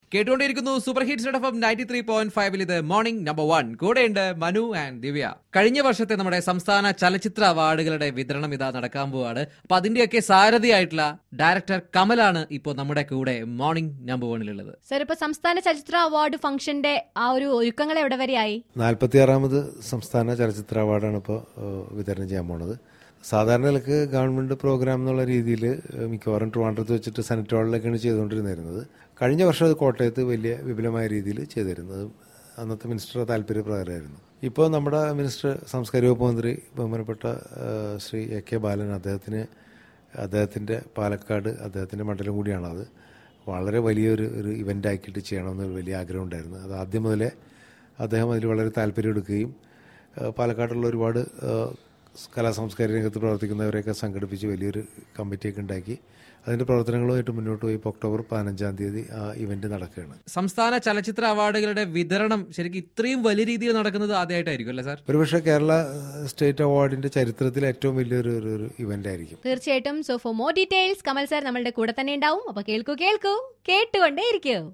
INTERVIEW WITH DIRECTOR KAMAL..